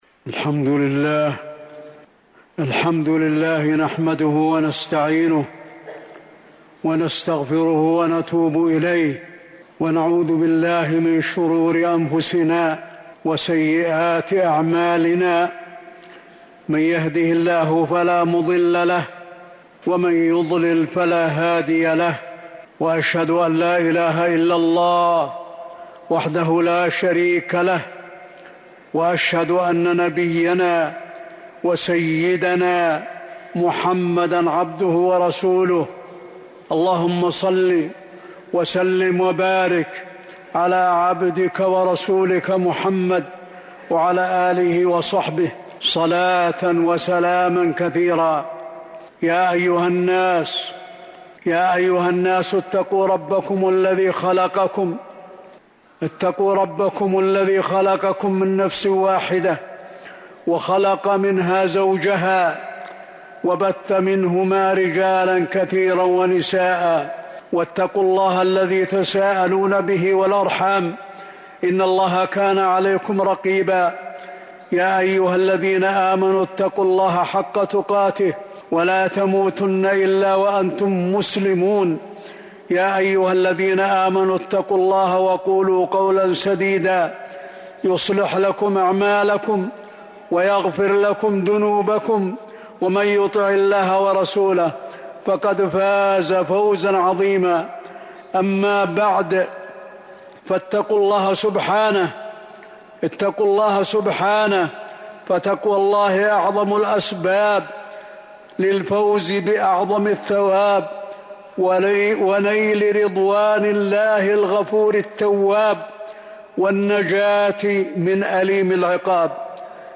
المدينة: عبور الصراط - علي بن عبد الرحمن الحذيفي (صوت - جودة عالية. التصنيف: خطب الجمعة